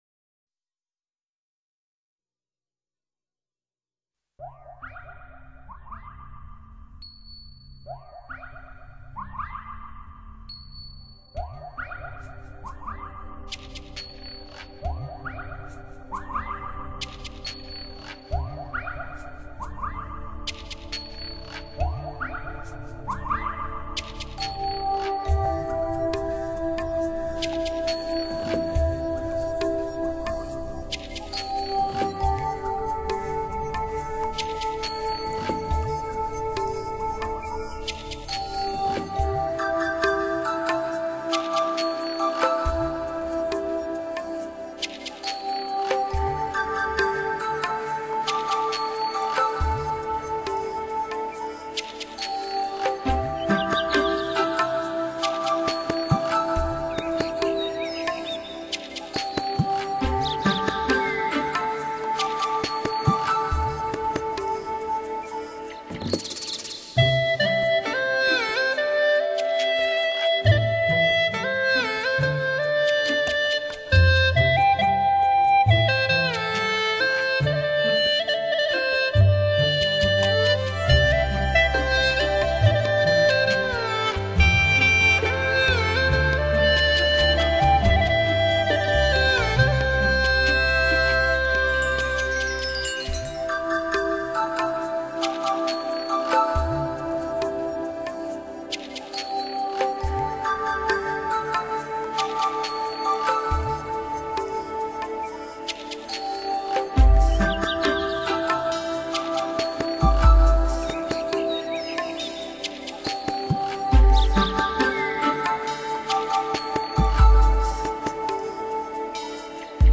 悠扬绵长的音色
轻如丝绸灵性质感无边无际纯天然的聆听感受
其音色轻柔细胞，圆润质朴，曲调深情委婉，极富表现力。